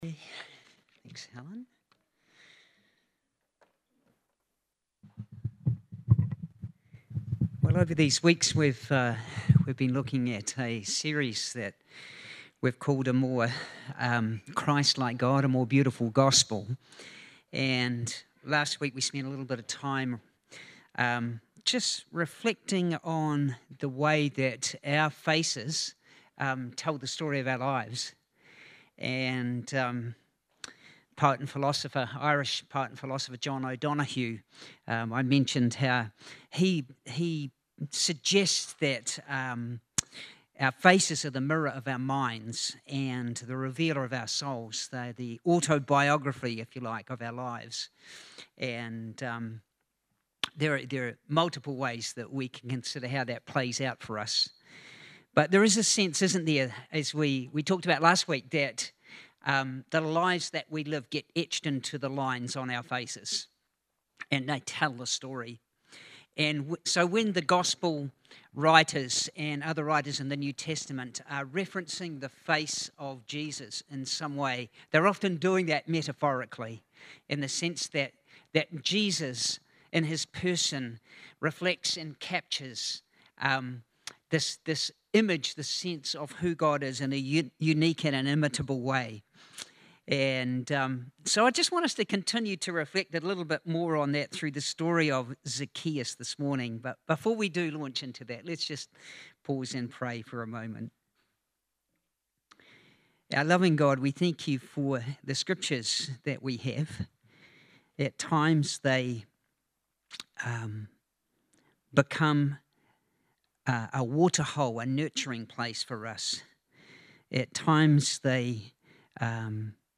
LUKE 19:1-10 SERMON